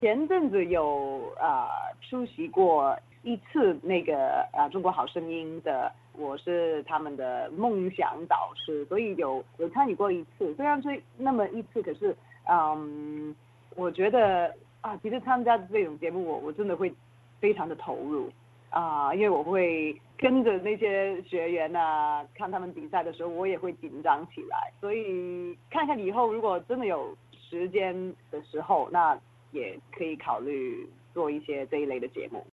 莫文蔚接受了SBS亚洲流行音乐节目PopAsia的英文采访，并用普通话聊起对中国唱歌类真人秀的看法。